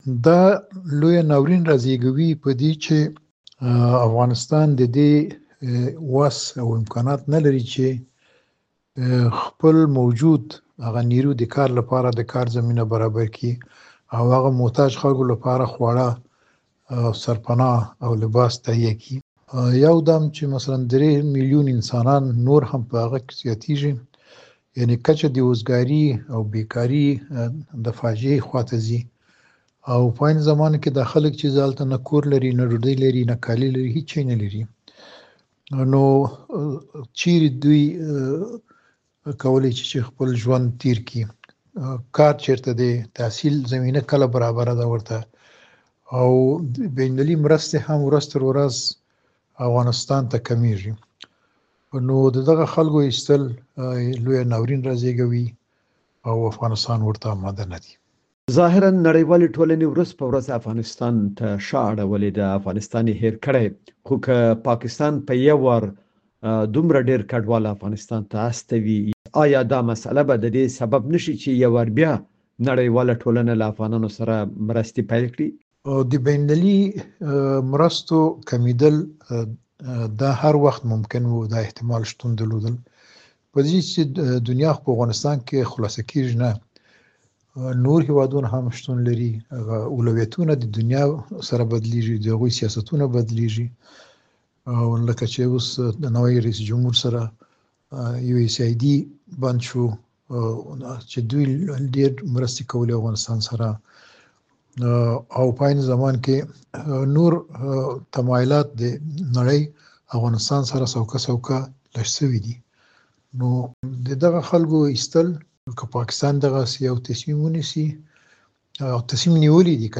مرکه - صدا